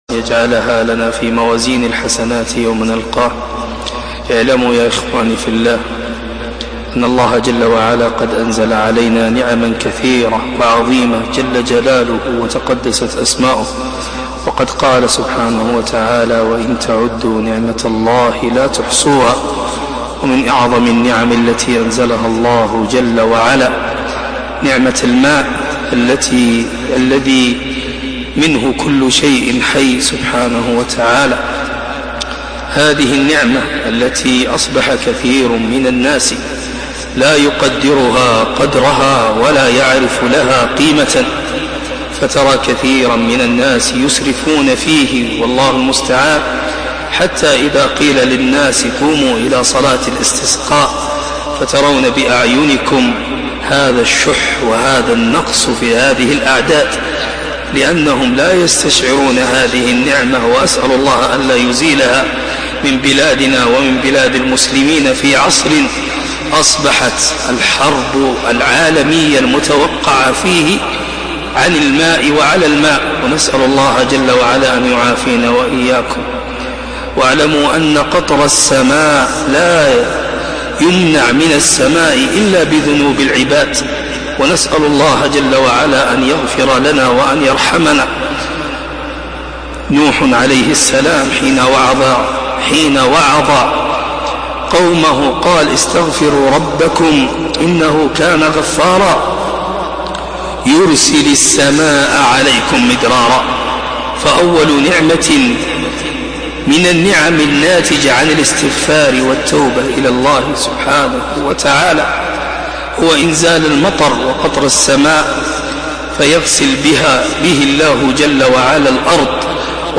خطبة الاستسقاء-